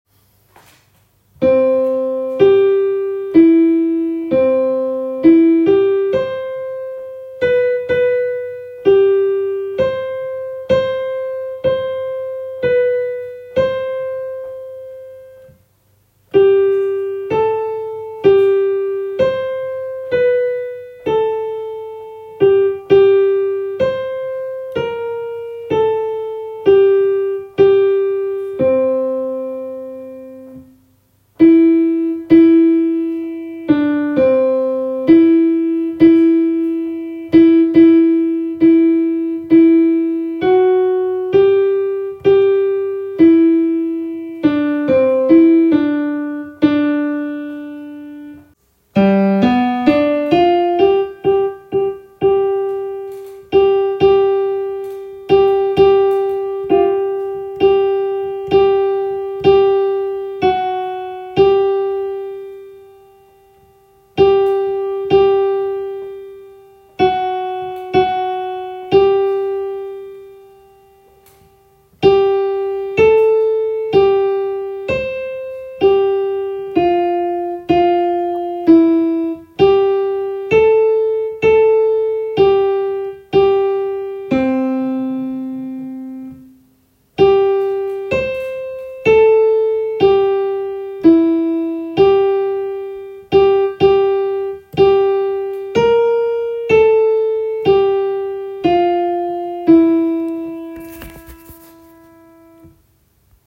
Kun koret
A(på tenorstemme)